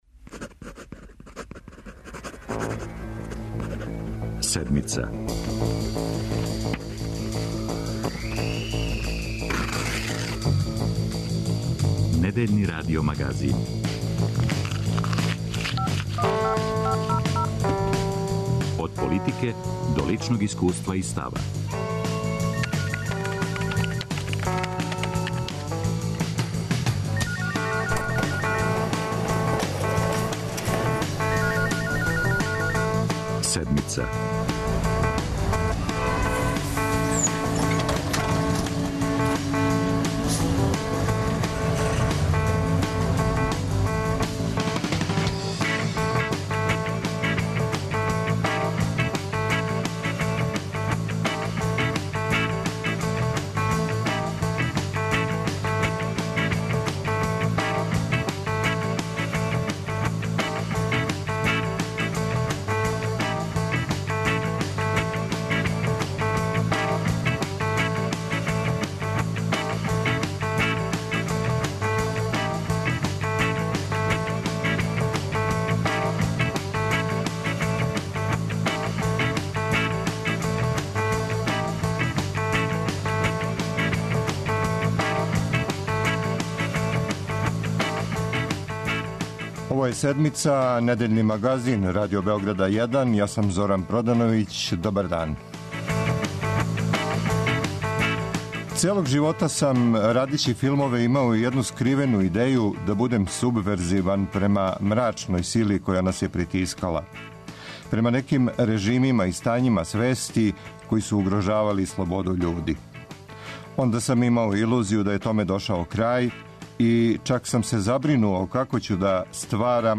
Овако за Седмицу говори Горан Марковић, филмски и позоришни редитељ, драмски писац.